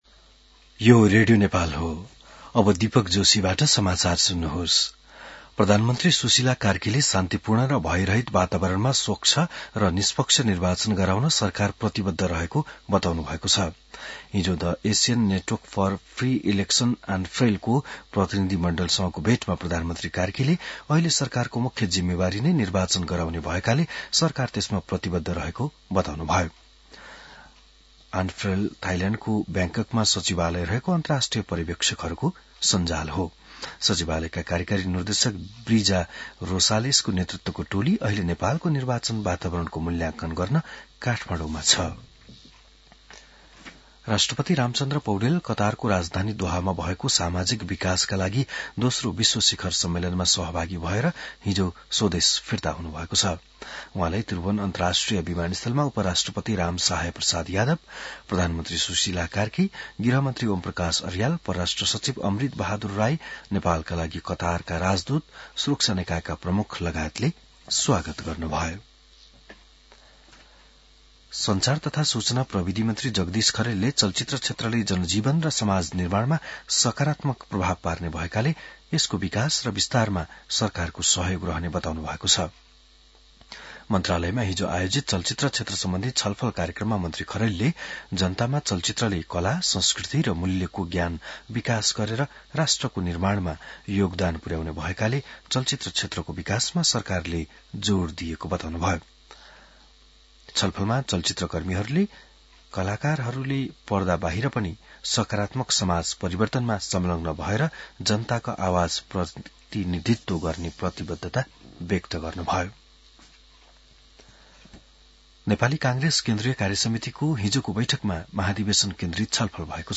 बिहान १० बजेको नेपाली समाचार : २१ कार्तिक , २०८२